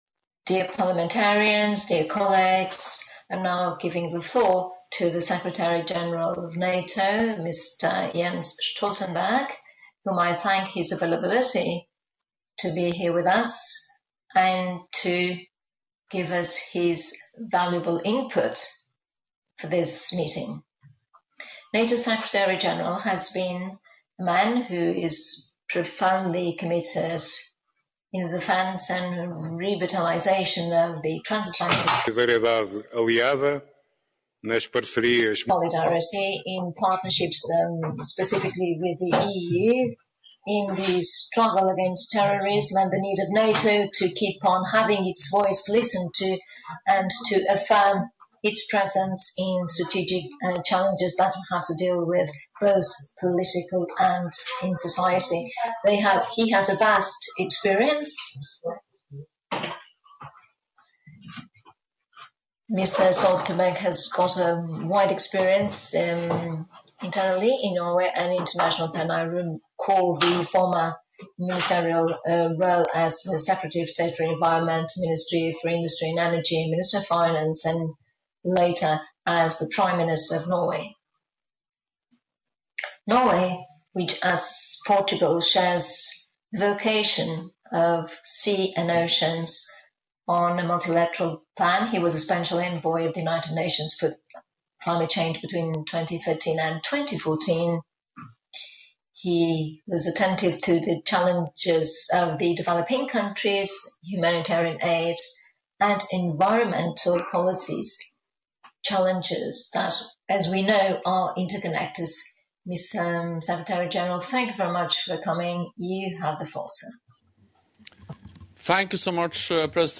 (As delivered)